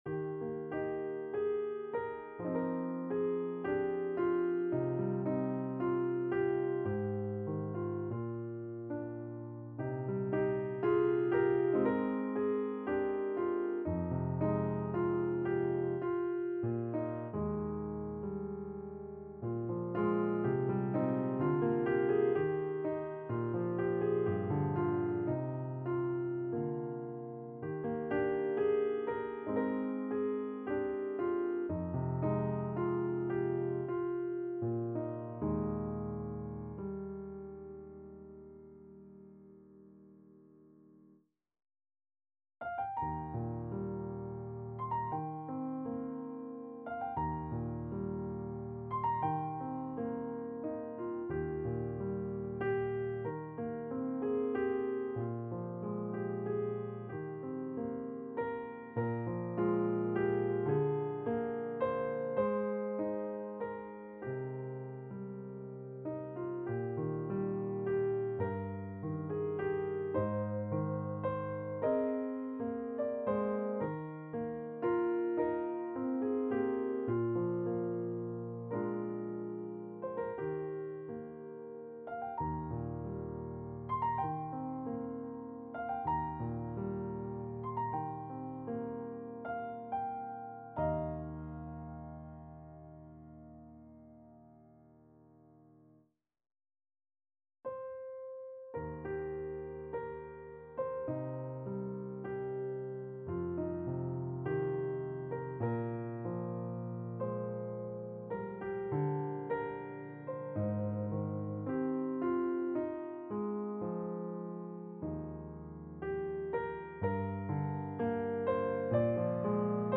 traditional and classic hymns
lever or pedal harp